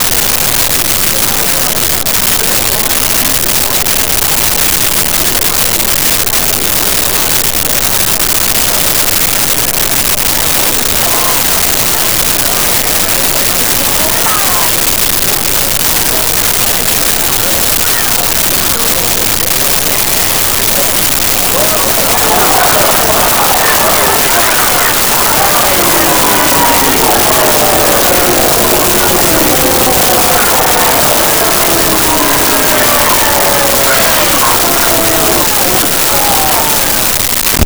Crowd Angry Boos 02
Crowd Angry Boos 02.wav